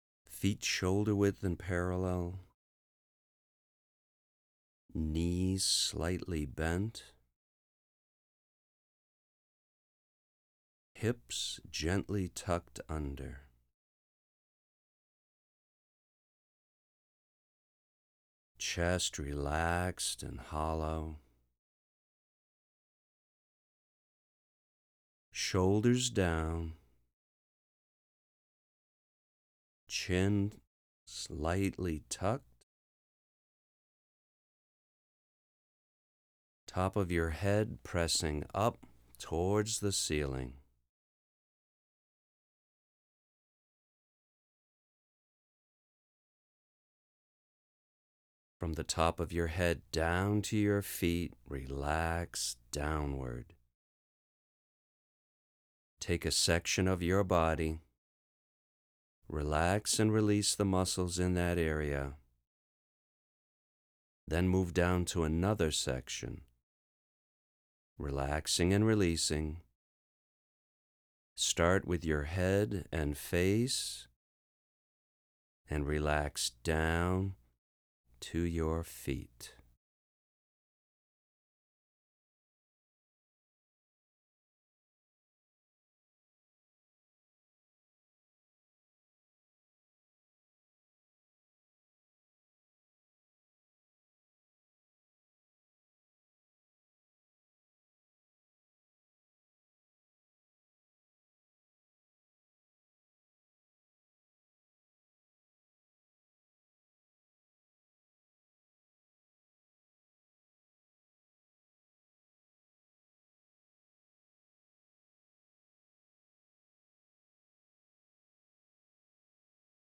Guided Meditation Audio
standing-meditation.wav